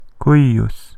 Between vowels, it was generally as a geminate /jj/, as in cuius (pronounced something like
La-cls-cuius.ogg.mp3